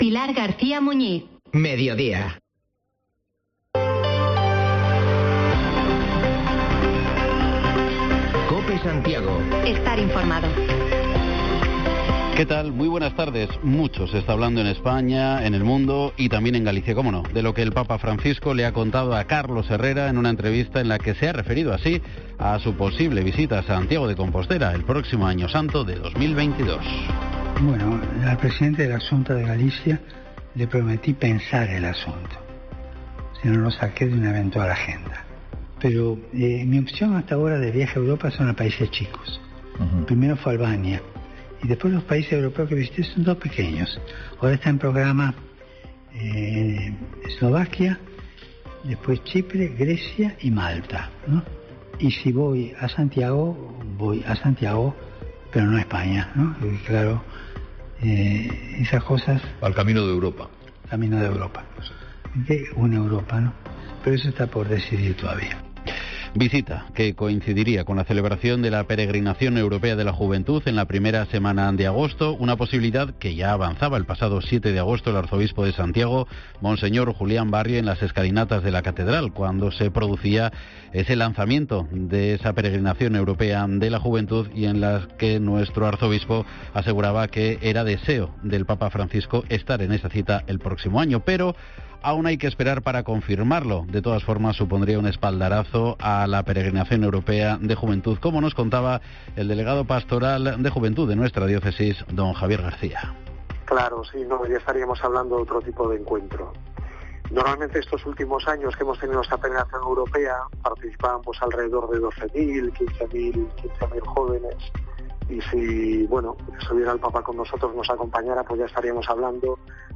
Podcast: Informativo local Mediodía en Cope Santiago y de las Rías 01/09/2021